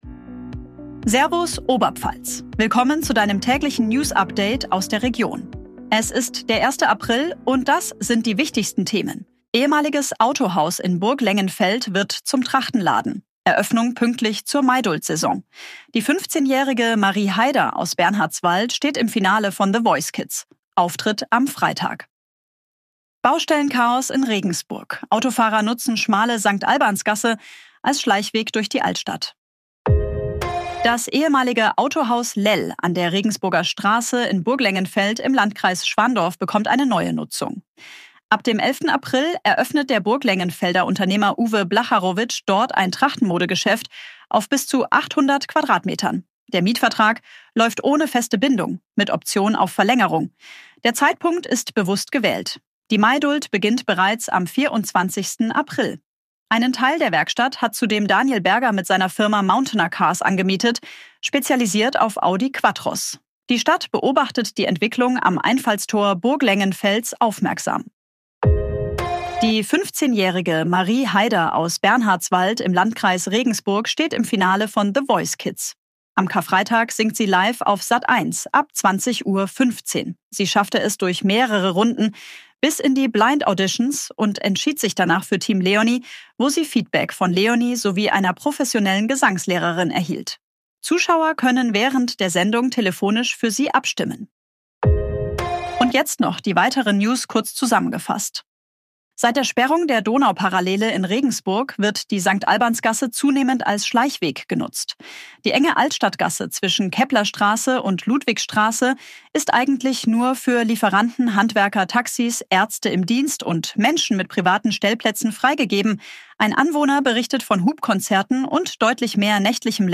Tägliche Nachrichten aus deiner Region
Unterstützung künstlicher Intelligenz auf Basis von redaktionellen